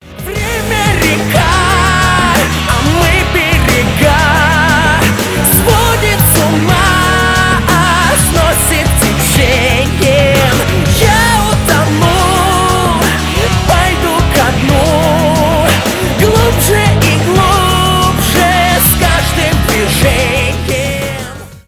мужской голос
поп